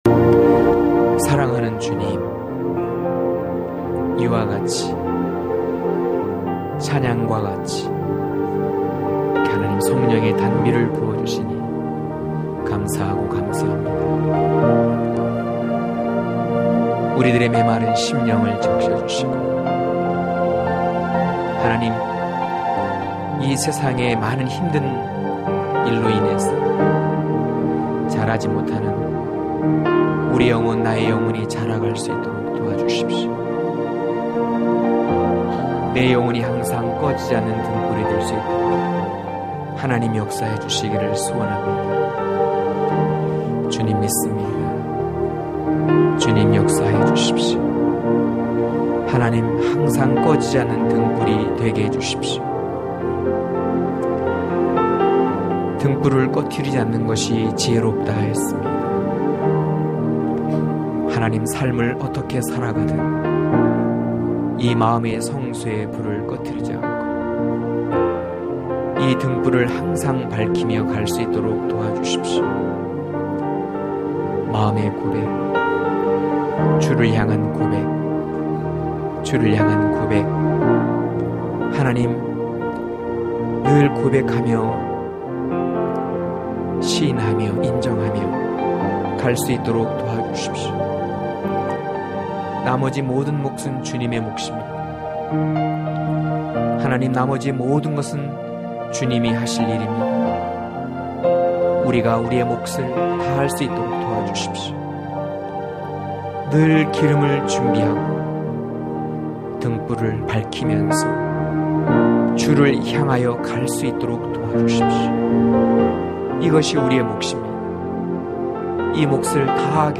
강해설교 - 04.고벨화...